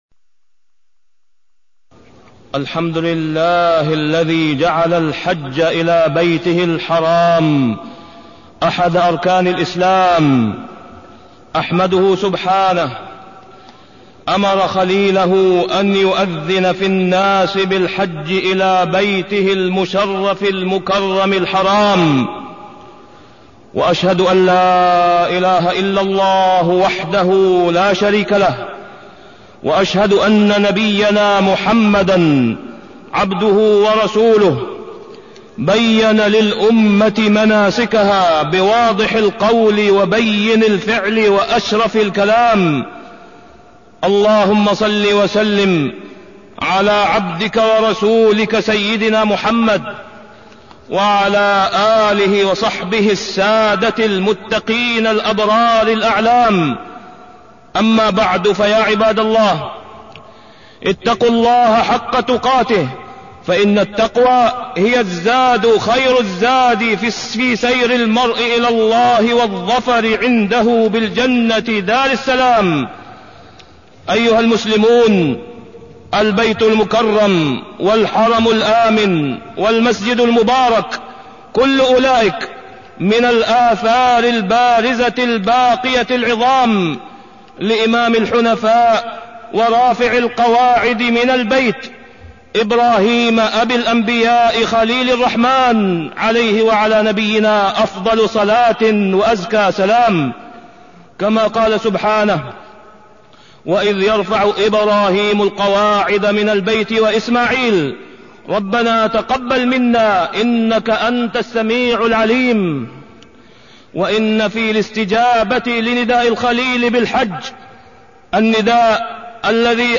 تاريخ النشر ١٥ ذو القعدة ١٤٢١ هـ المكان: المسجد الحرام الشيخ: فضيلة الشيخ د. أسامة بن عبدالله خياط فضيلة الشيخ د. أسامة بن عبدالله خياط قصد البيت الحرام